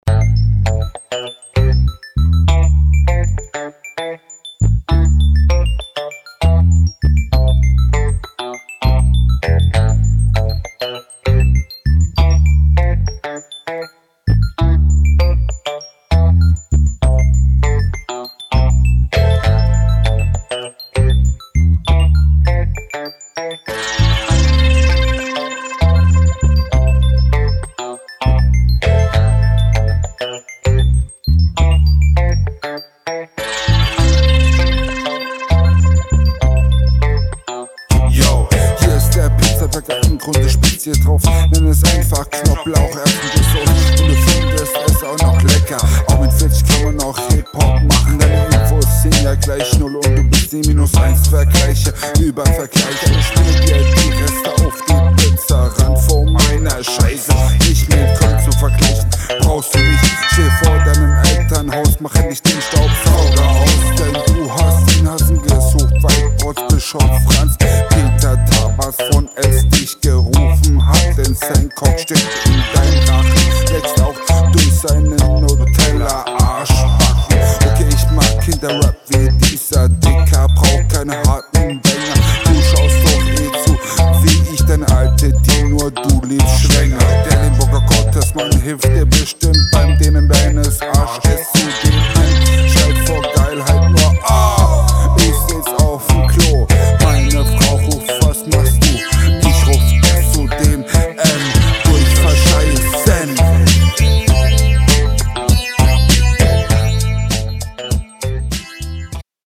40 Sekunden Intro oof oof, Flowlich hinkt das träge hinterm Beat hinterher.
bruder ich verstehe kein wort